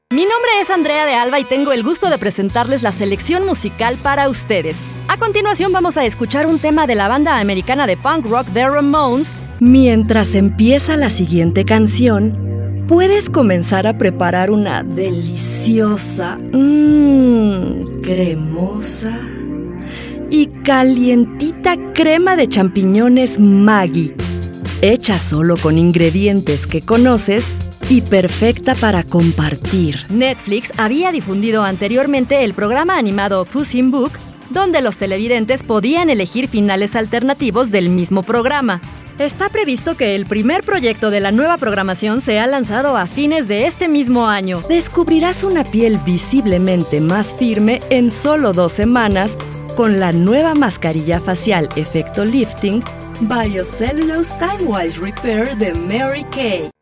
Female Voice Talent in Spanish (Latin American) and English
Commercial Demo 2
Latin American, Mexican